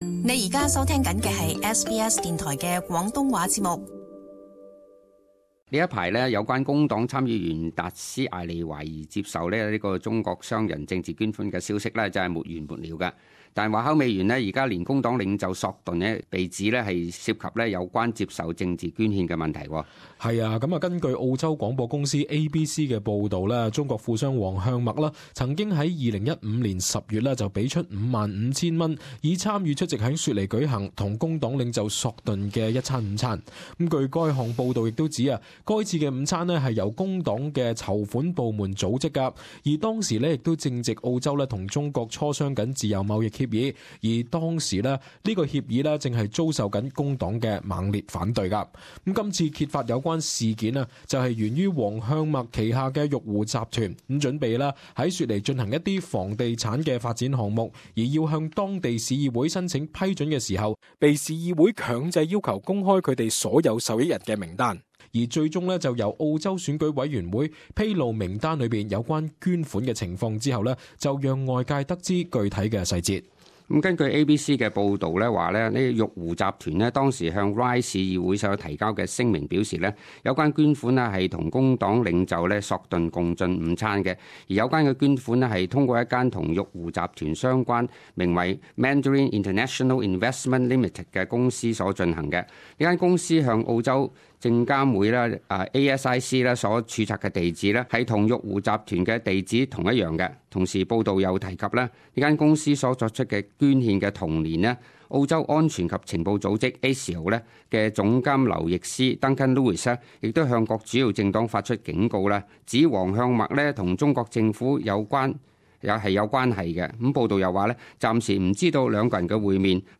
【時事報導】報導指工黨領袖索頓捲入政治捐獻風波